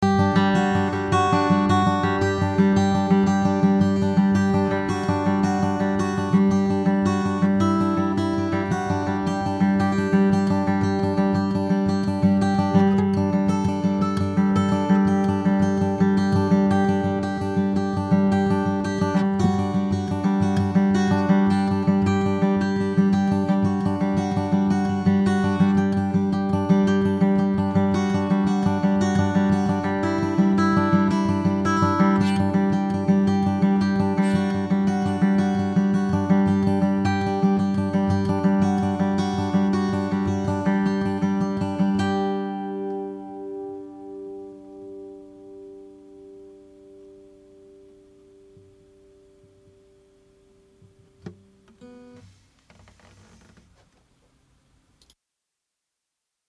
SM57での録音テスト。
ソースはアコギ。
思うに57だとややレンジの狭さを感じるものの割りと十分な音質で録れるようです。
問題は感度の問題で上の方のノイズが載りやすいように思います。
高音域がものたりないのはなにかしらEQで調整してあげる必要があると思いますが、ノイズも一緒に持ち上がってきますね。